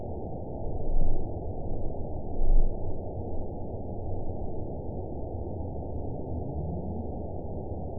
event 910683 date 01/29/22 time 01:54:41 GMT (3 years, 10 months ago) score 8.79 location TSS-AB08 detected by nrw target species NRW annotations +NRW Spectrogram: Frequency (kHz) vs. Time (s) audio not available .wav